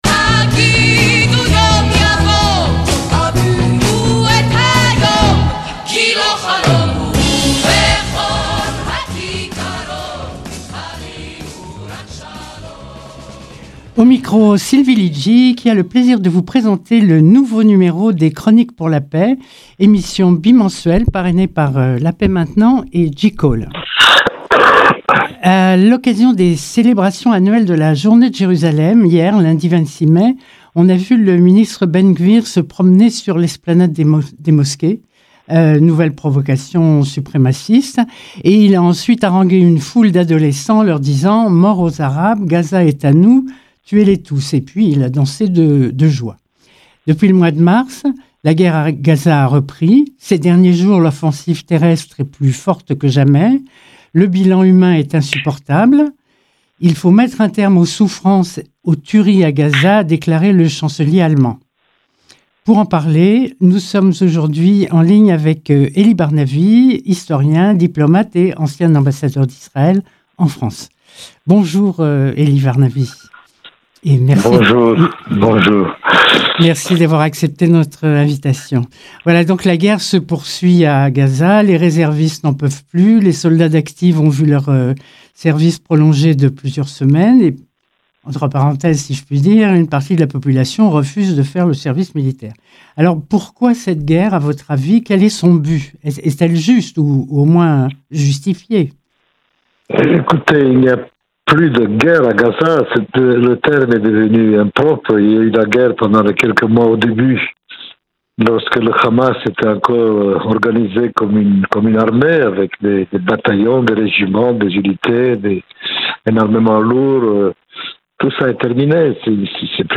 émission bimensuelle de Radio Shalom parrainée par La Paix Maintenant et JCall